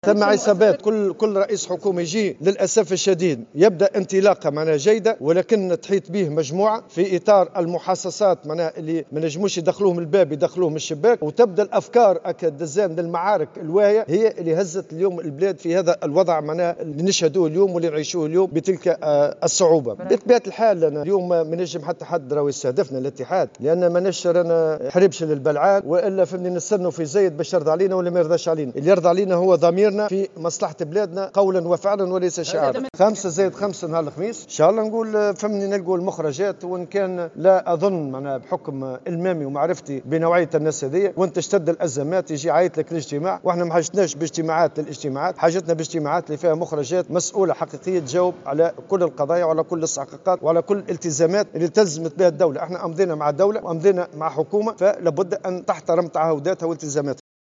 وفي تصريح للجوهرة أف أم ، أكد الطبوبي أنه لايأمل الكثير بحكم معرفته وإلمامه بنوعية مسؤولي الدولة الذين لا يدعون للإجتماع مع ممثلي الإتحاد إلا عندما تشتد الأزمات وفق قوله.